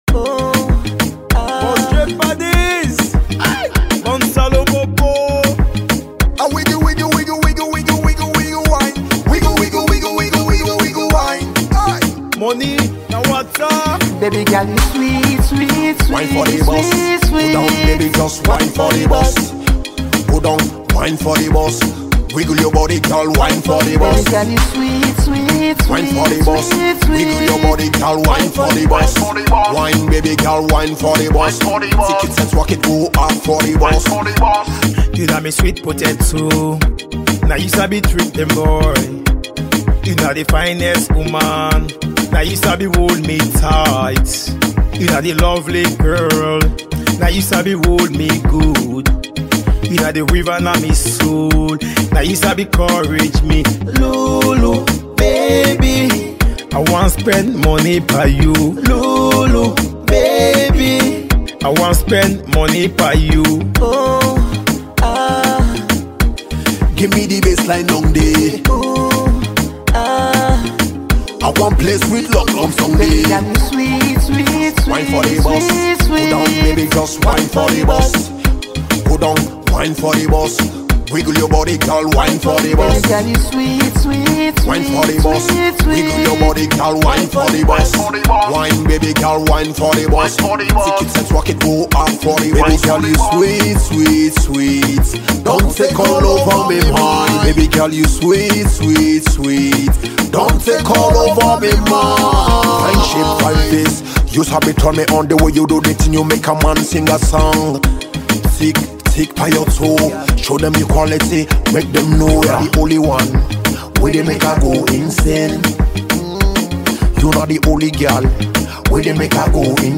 Australian-based Sierra Leonean-born Afrobeat singer